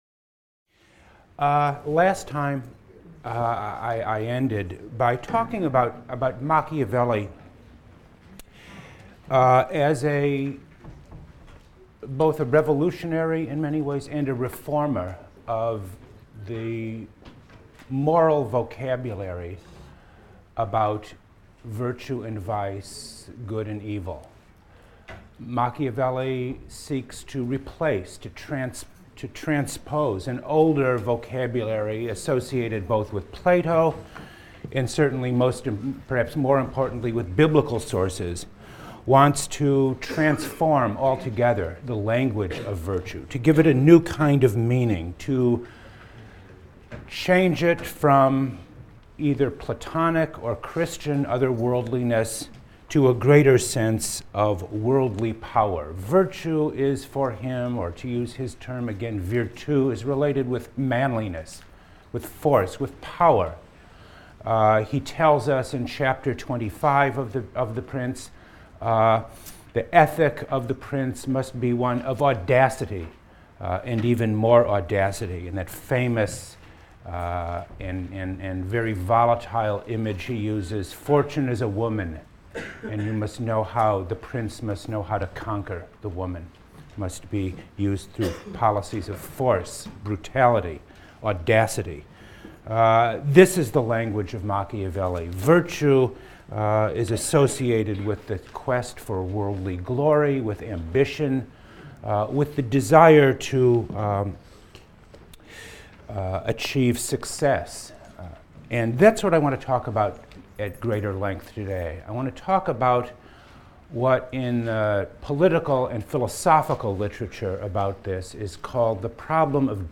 PLSC 114 - Lecture 11 - New Modes and Orders: Machiavelli, The Prince (chaps. 13-26) | Open Yale Courses